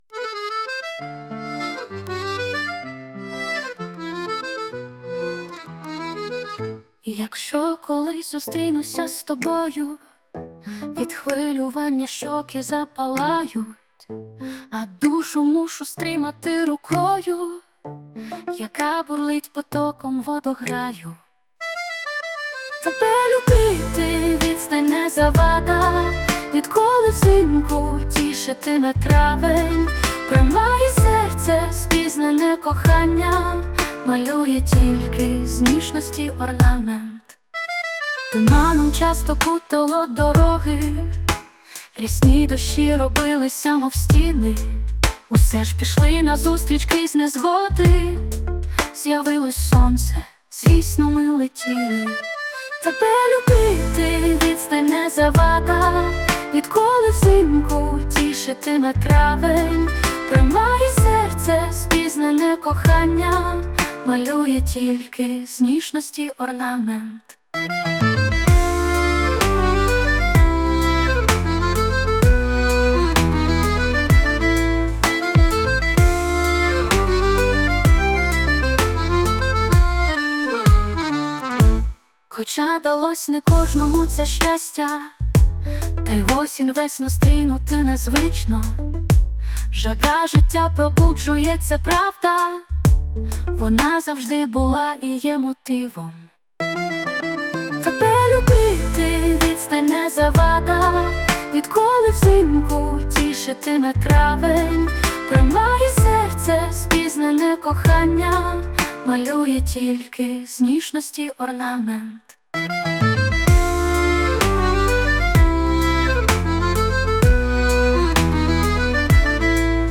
Музична композиція створена за допомогою SUNO AI
СТИЛЬОВІ ЖАНРИ: Ліричний